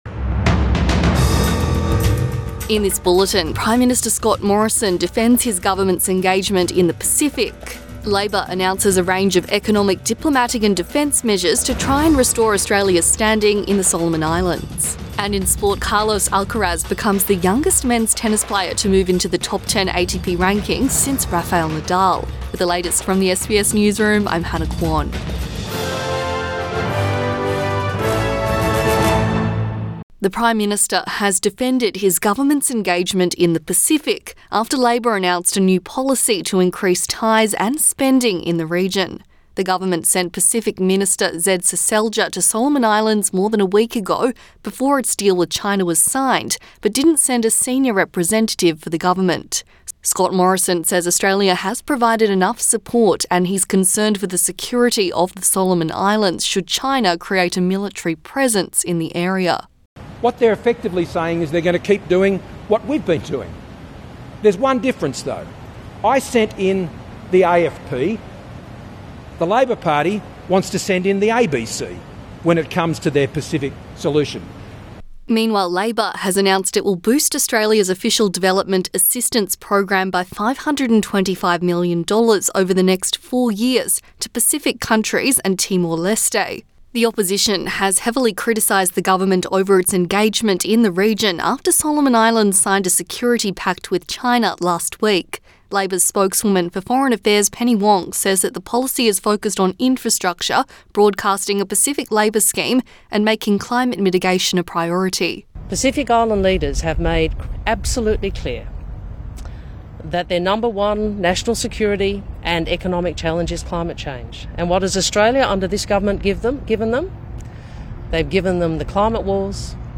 Midday bulletin 26 April 2022